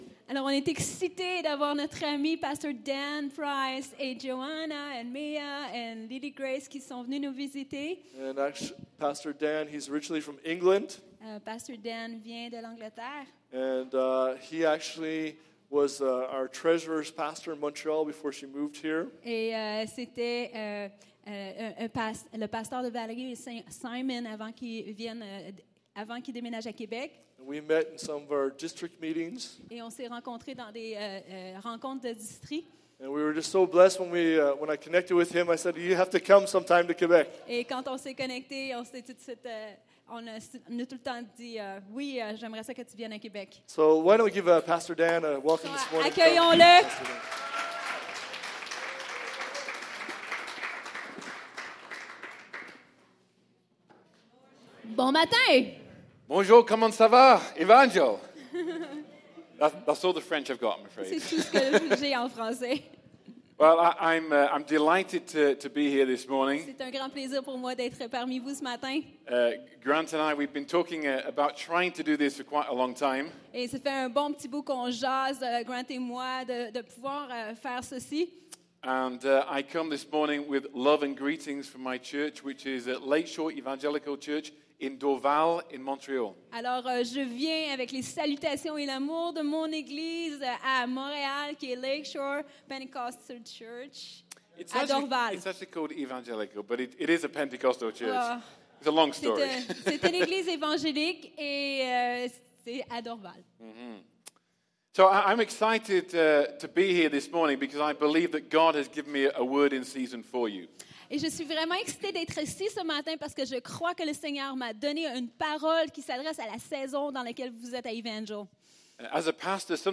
Sermons | Evangel Pentecostal Church